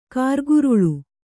♪ kārguruḷu